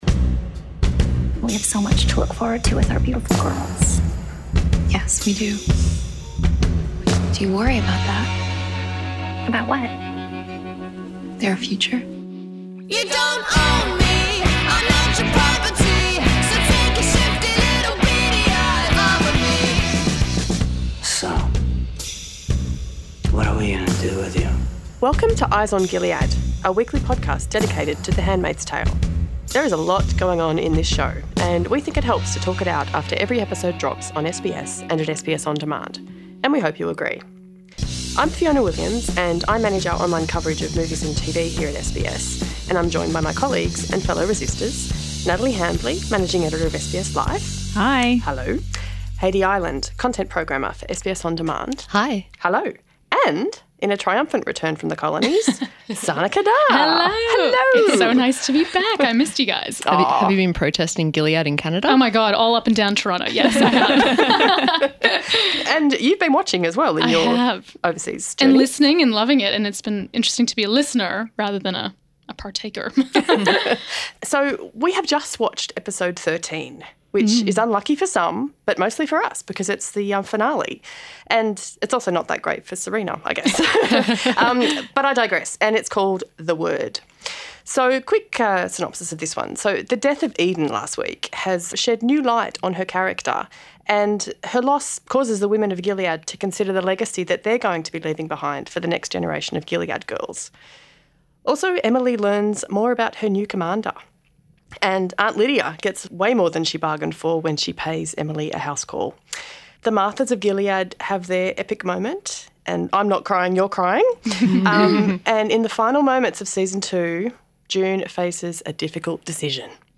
There's so much to unpack, and we've got a full panel for the occasion.